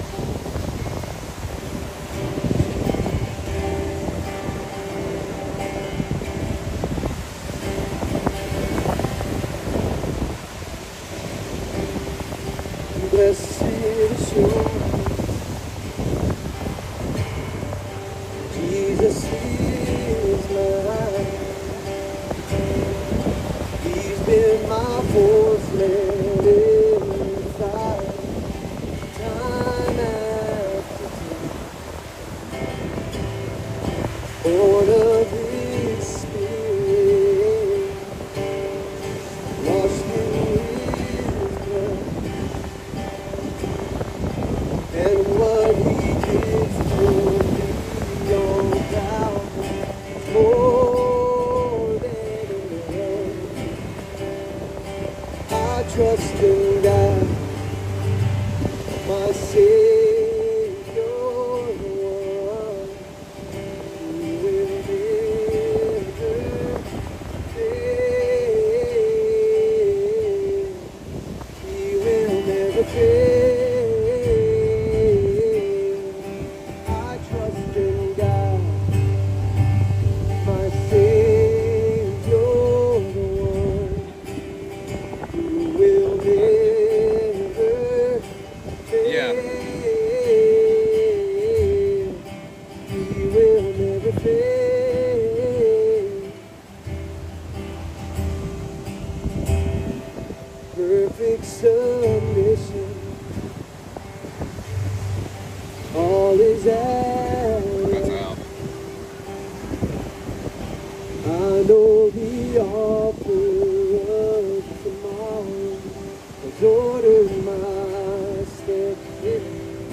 Download Download Reference Leviticus 13:45-46; Matthew 26:39; Mark 1:40-45; Romans 8:28; 2 Corinthians 5:21, 12:8-9b Sermon Notes Click Here for Notes 250608.pdf QUESTIONS FROM MARK: ARE YOU WILLING?